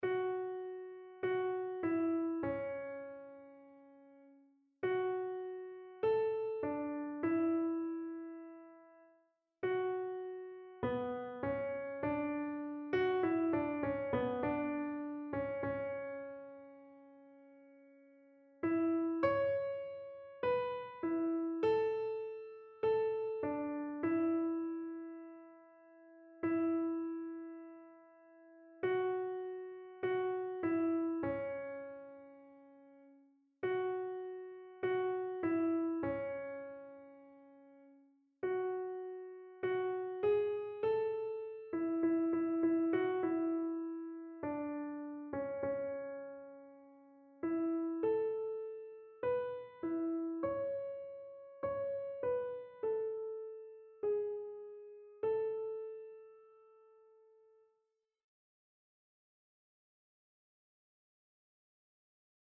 Errettung des Sünders Evangeliumslieder Kinderlied
Notensatz 1 (Unisono)